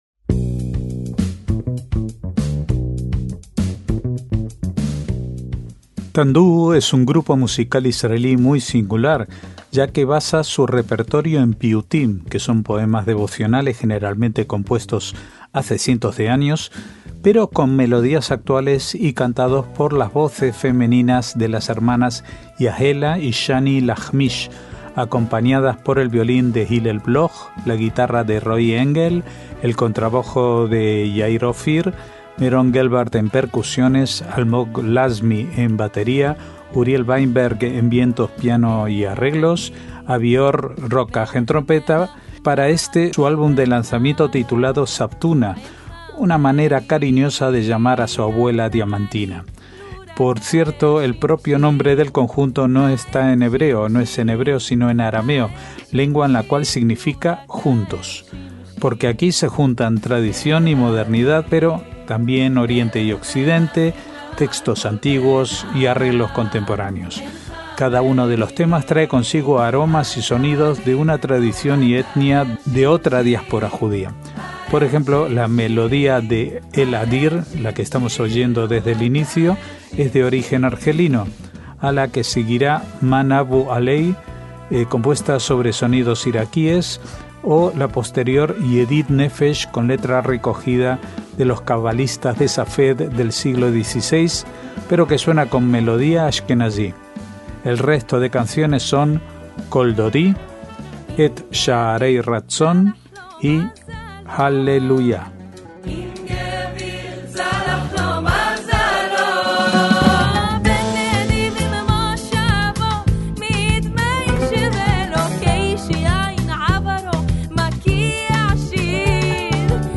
MÚSICA ISRAELÍ
con melodías actuales
cantados por las voces femeninas
violín
contrabajo
trompeta